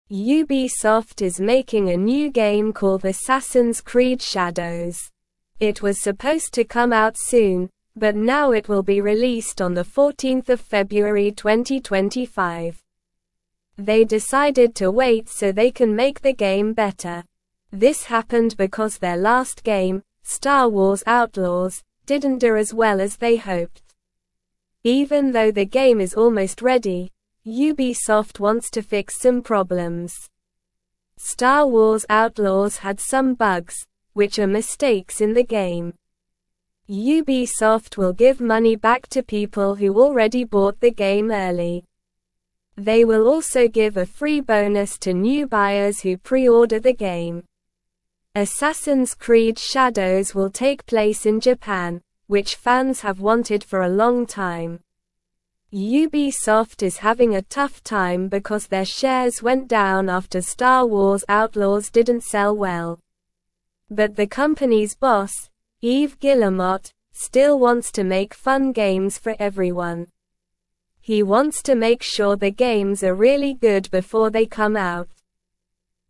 Slow
English-Newsroom-Lower-Intermediate-SLOW-Reading-Ubisoft-delays-new-game-to-make-it-better.mp3